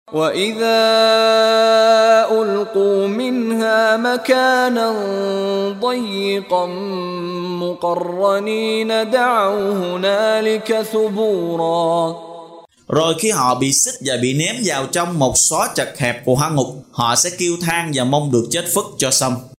Đọc ý nghĩa nội dung chương Al-Furqan bằng tiếng Việt có đính kèm giọng xướng đọc Qur’an